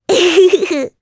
Rephy-Vox-Laugh_jp.wav